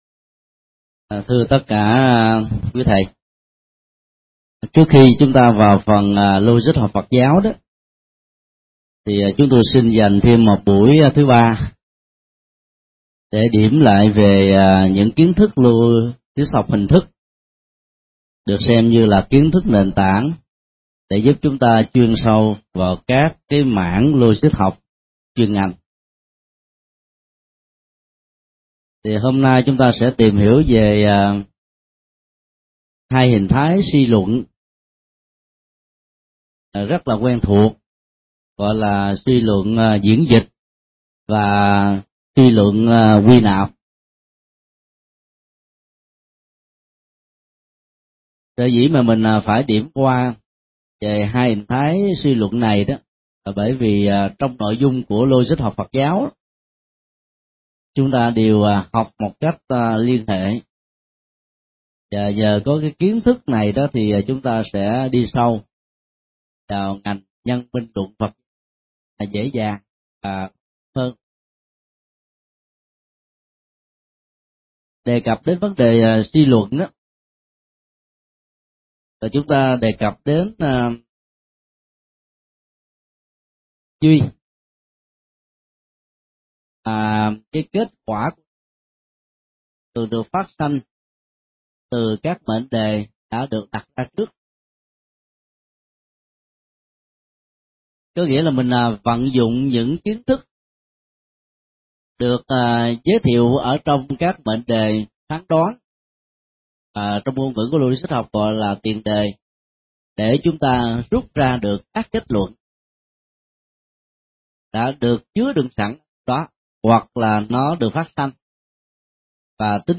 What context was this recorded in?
Giảng tại Học viện Phật giáo Việt Nam tại TP.HCM, ngày 3 tháng 10 năm 2007